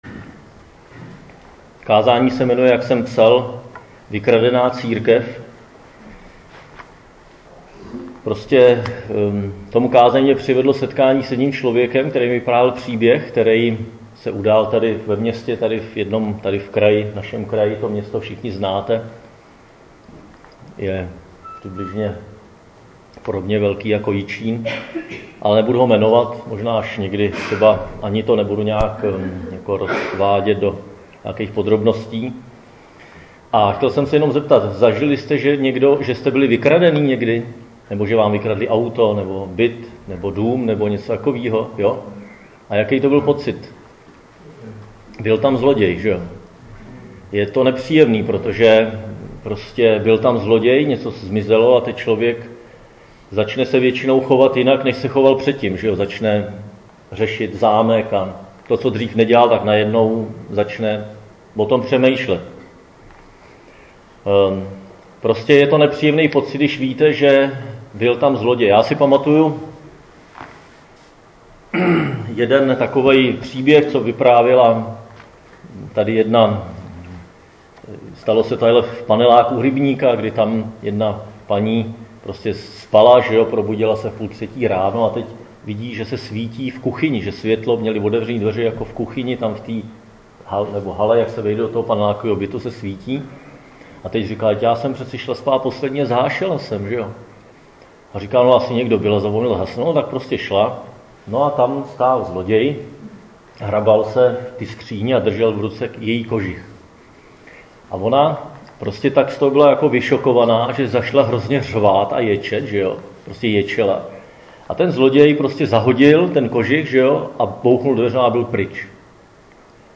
Křesťanské společenství Jičín - Kázání 31.12.2017